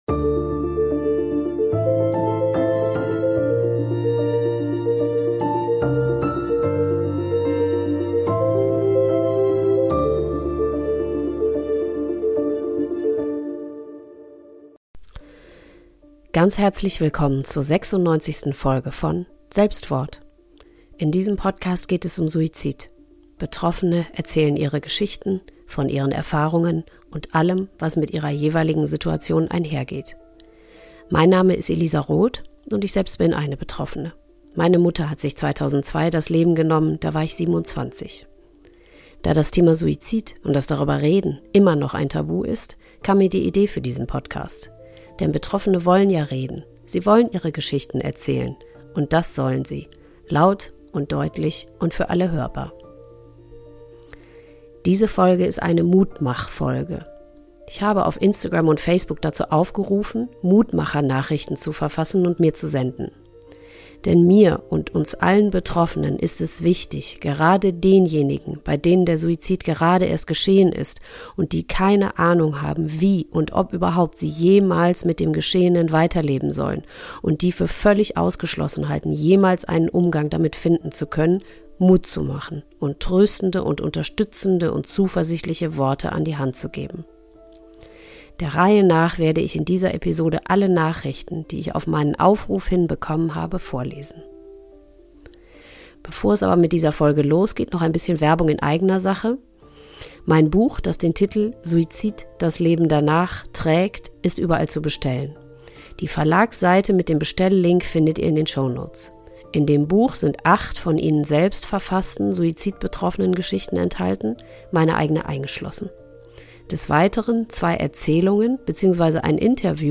Für diese Folge habe ich mutmachende Nachrichten bei euch eingesammelt, die ich hier vorlese.